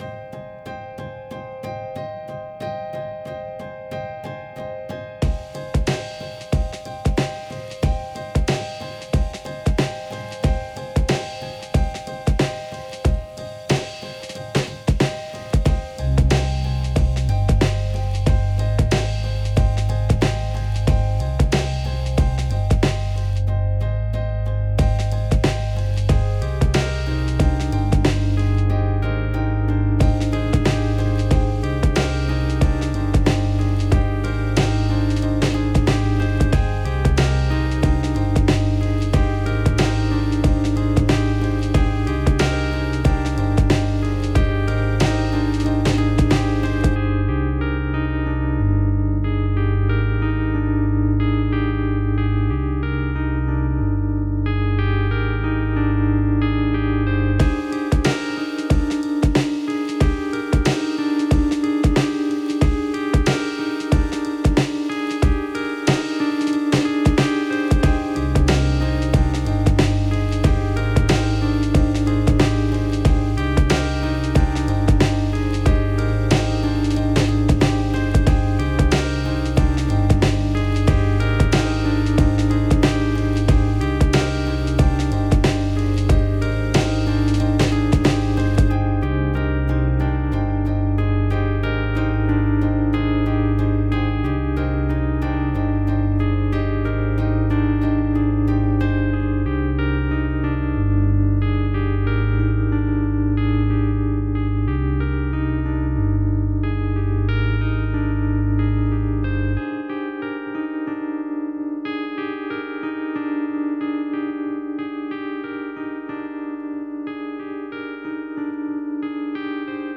BASE ACOMPAÑAMENTO 3